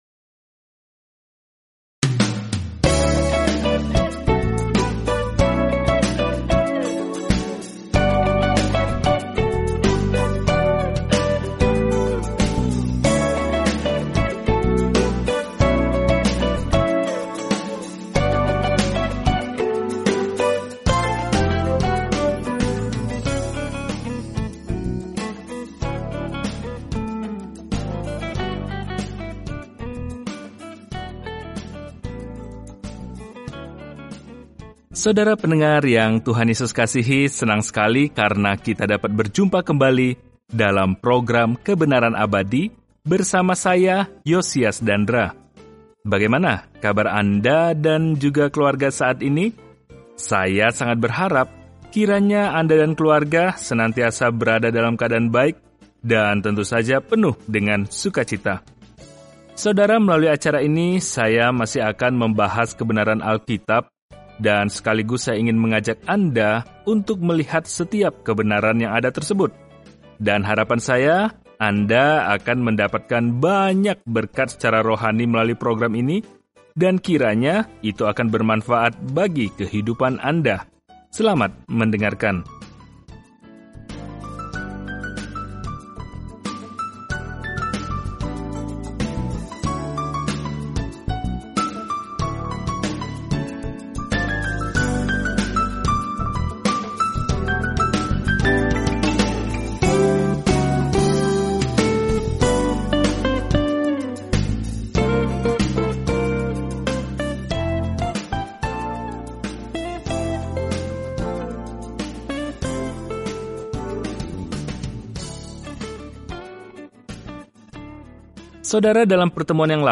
Firman Tuhan, Alkitab Maleakhi 2:10-17 Hari 8 Mulai Rencana ini Hari 10 Tentang Rencana ini Maleakhi mengingatkan Israel yang terputus bahwa dia memiliki pesan dari Tuhan sebelum mereka mengalami keheningan yang lama – yang akan berakhir ketika Yesus Kristus memasuki panggung. Jelajahi Maleakhi setiap hari sambil mendengarkan pelajaran audio dan membaca ayat-ayat tertentu dari firman Tuhan.